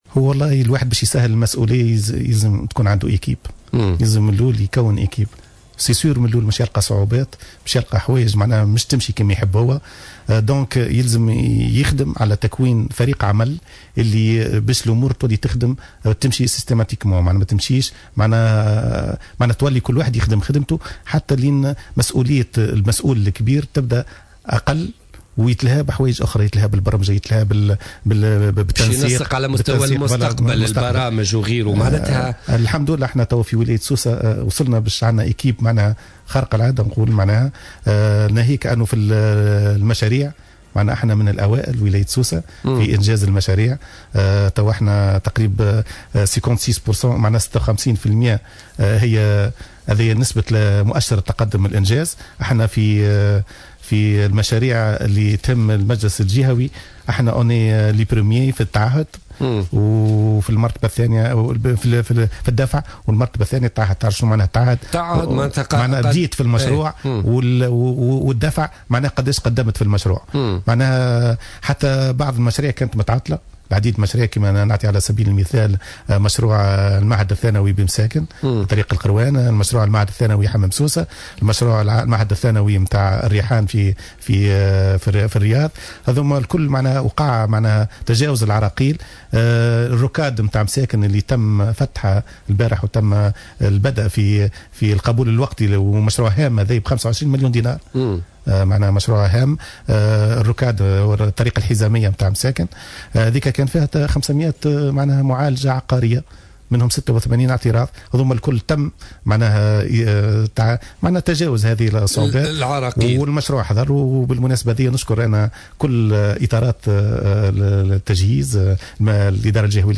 Invité sur le plateau de Jawhara Fm, le gouverneur de Sousse, Abdelmalek Sellami a indiqué mercredi 28 janvier 2015, que la région de Sousse figure à la première place sur le plan national au niveau de l’avancement de la réalisation des projets où le taux a atteint, jusqu’ici, 56%.